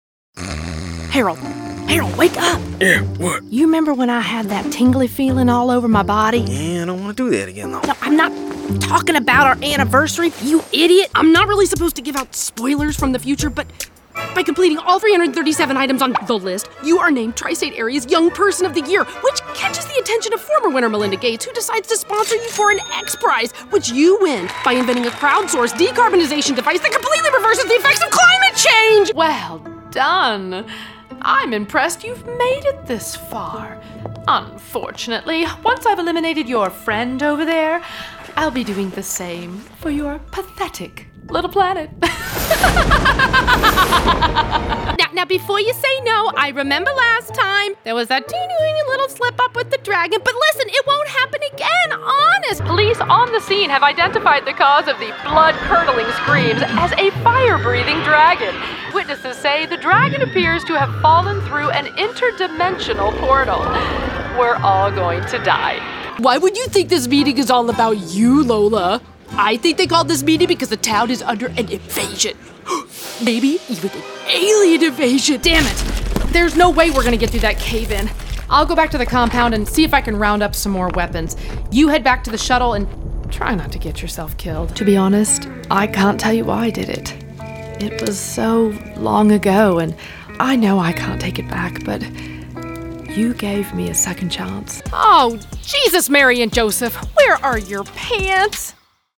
Animation/Character Demo
General American, East Texas, Pacific Northwest, California, Southern, Character Brooklyn